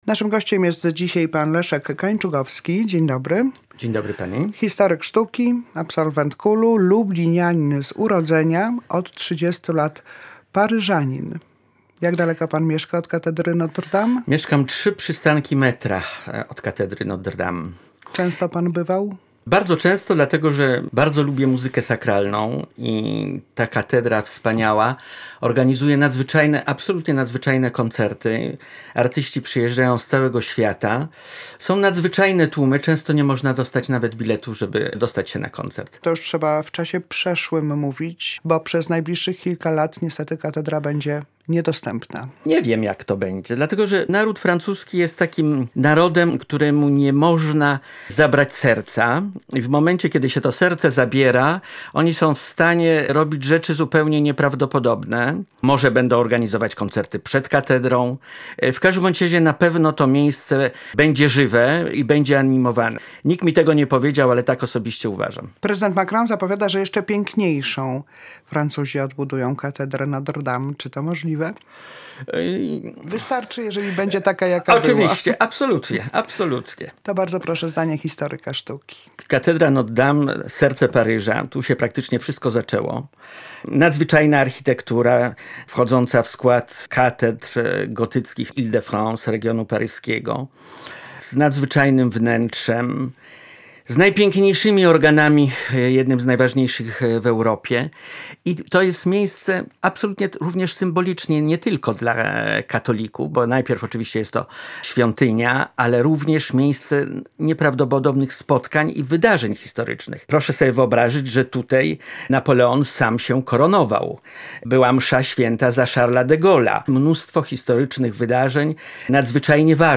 mówił na antenie Polskiego Radia Lublin